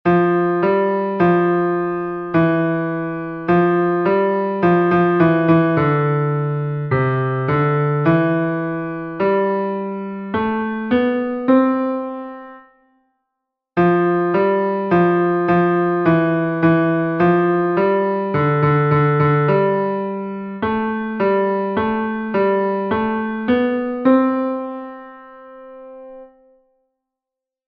keeping the beat exercise 2